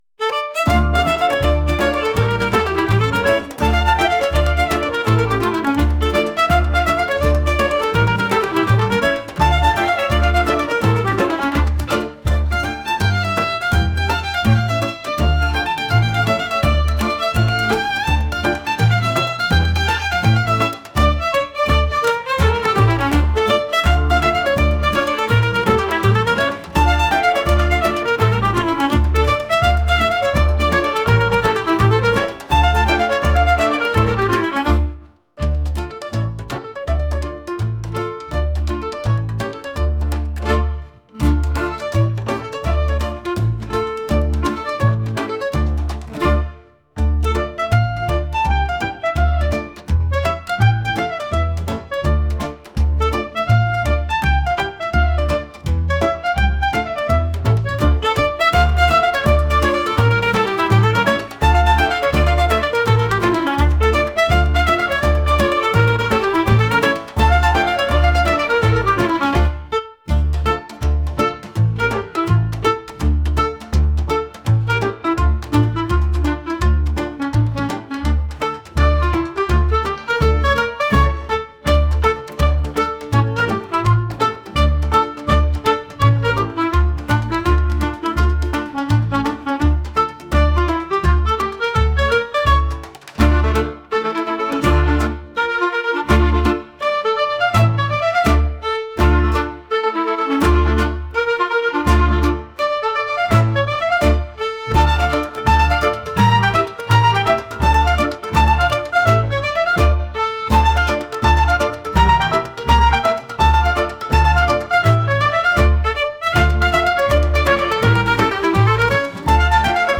world | lively